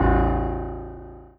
piano-ff-01.wav